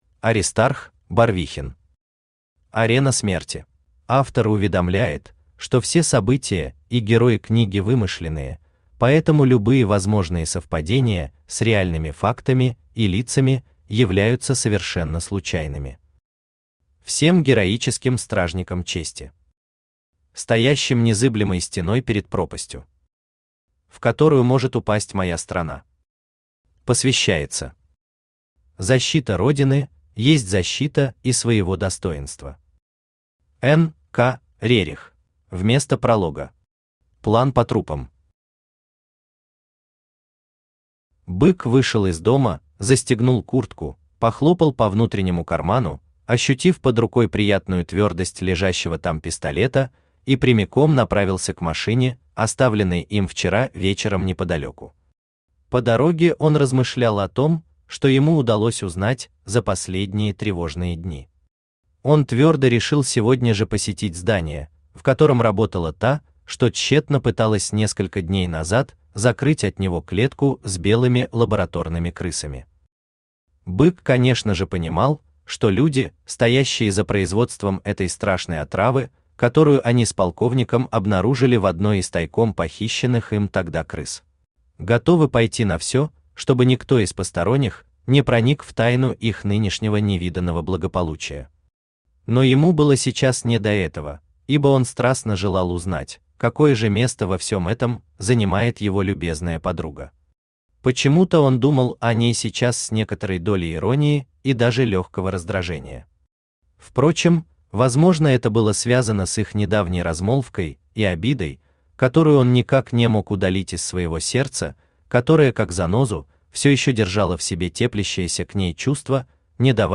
Aудиокнига Арена смерти Автор Аристарх Барвихин Читает аудиокнигу Авточтец ЛитРес.